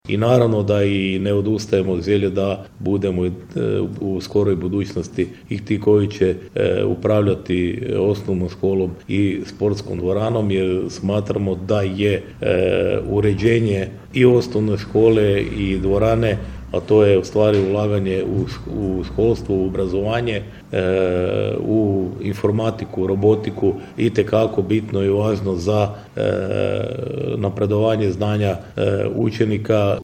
Prelog će iduće godine u listopadu biti domaćin Prvenstvu Hrvatske u karateu, najavljeno je ovog tjedna na održanoj konferenciji za medije u gradskoj vijećnici.